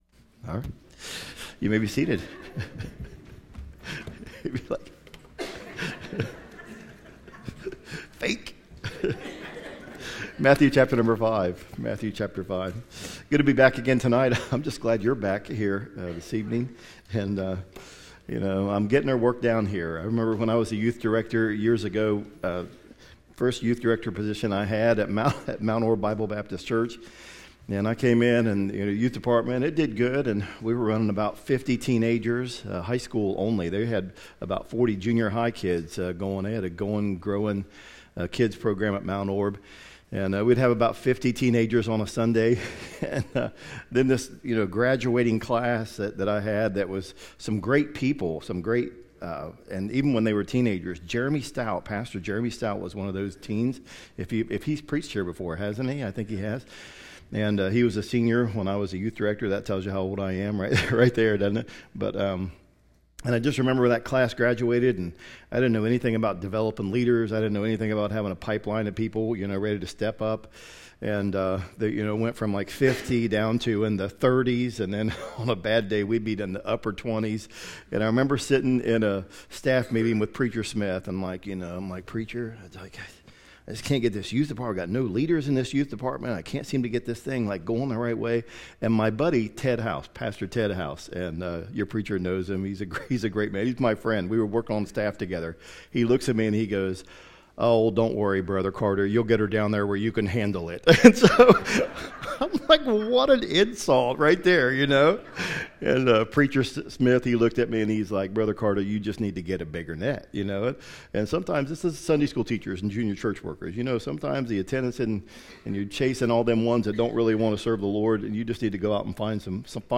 Fall Revival 2024 Sincere Up